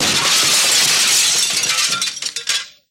На этой странице собраны звуки, связанные с зеркалами: от едва уловимых отражений до резких ударов.
Звук разбитого большого зеркала